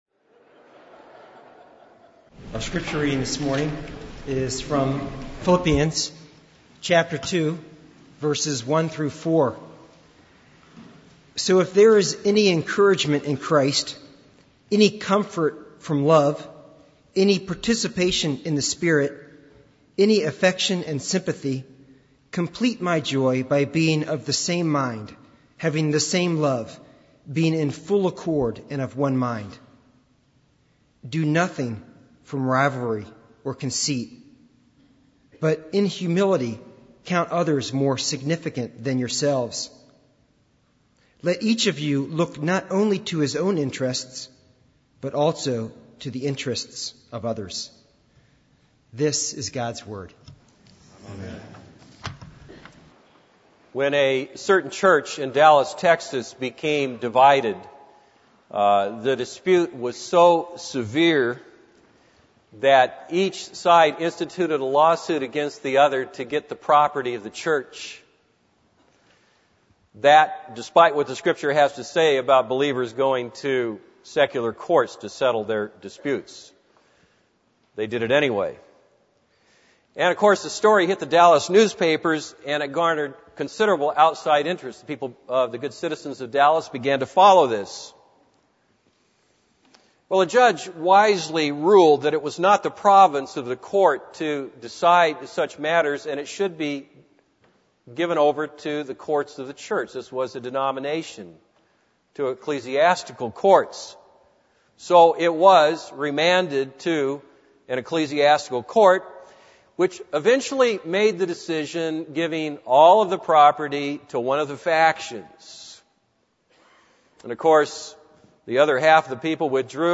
This is a sermon on Philippians 2:1-4.